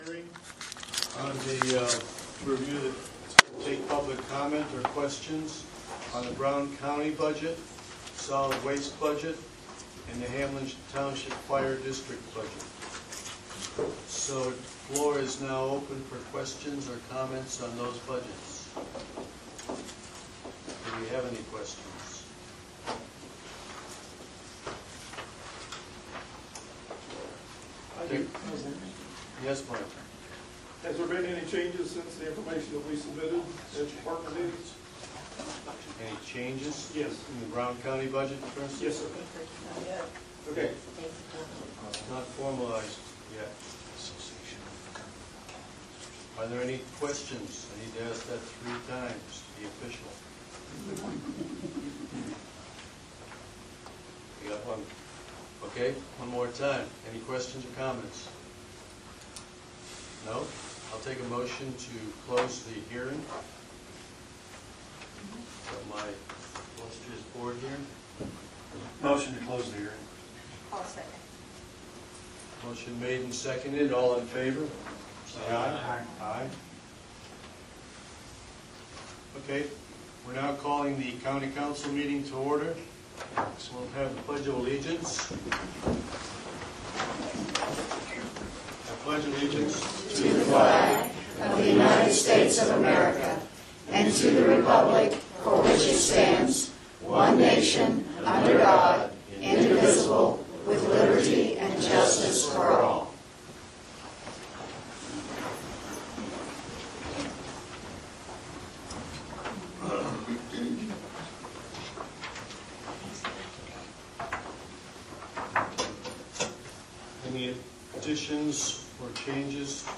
This post at the Facebook Group – Brown County Matters Audio of the Meeting. Discussion on the state of county finances at the beginning of the meeting and election integrity at the end.
The first part of the meeting was a presentation on the financial state of the county – not good.